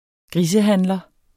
Udtale [ ˈgʁisə- ]